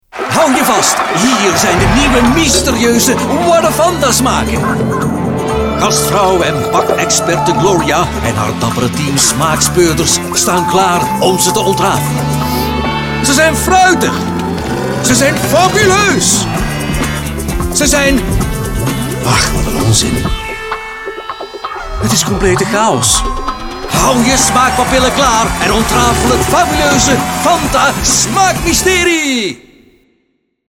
Male
Flemish (Native)
Corporate, Energetic, Engaging, Friendly, Natural, Smooth
Microphone: Neumann U89 + Brauner Phantom C + Sennheiser MKH 416